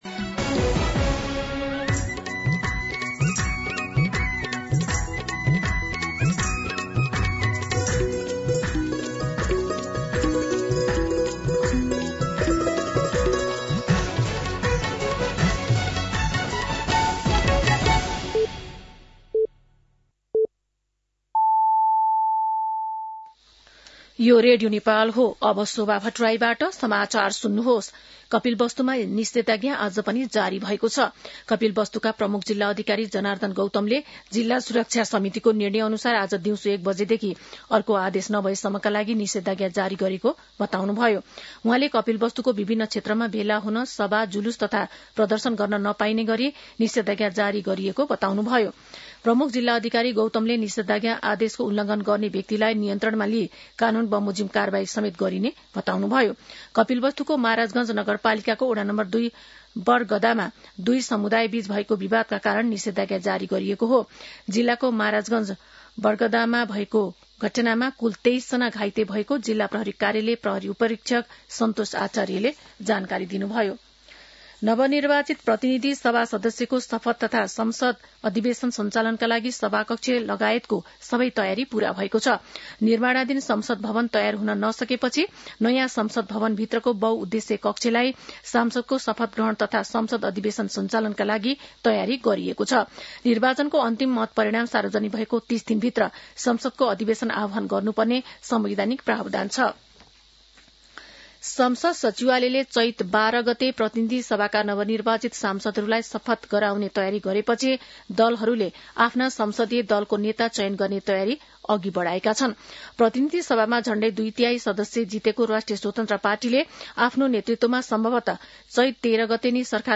दिउँसो ४ बजेको नेपाली समाचार : ८ चैत , २०८२
4-pm-Nepali-News-5.mp3